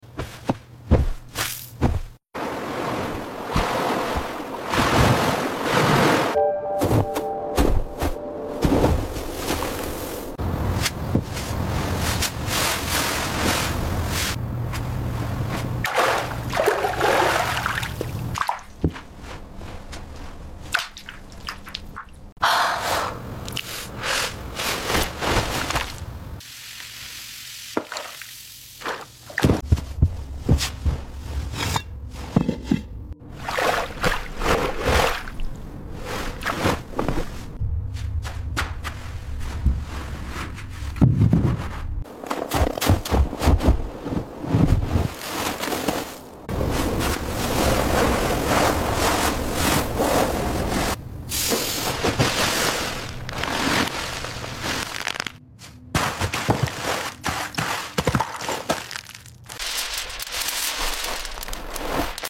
Asmr relajante camas satisfactorias 🛌